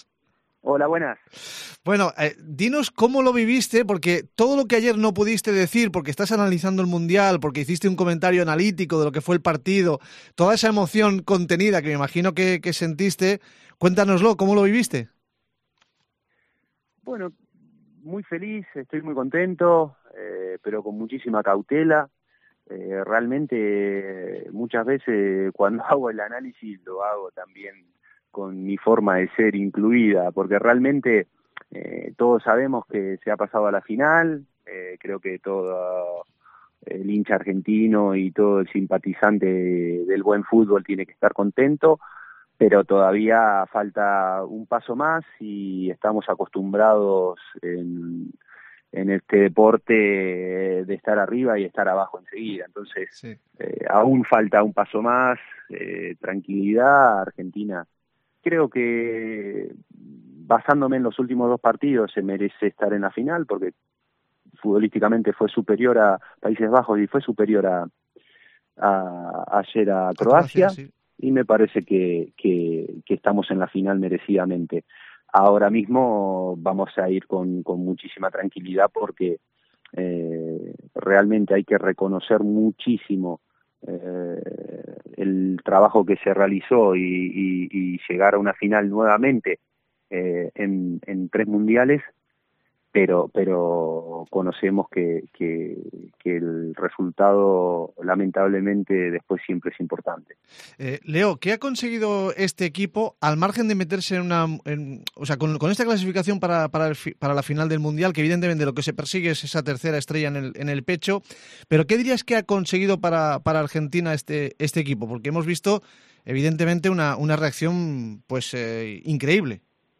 Charlamos con el ex portero del RCD Mallorca, Leo Franco, actual analista en Gol Mundial sobre la clasificación de Argentina para la final del Mundial y le preguntamos qué cree que ha aportado Lionel Scaloni a la dirección de su selección.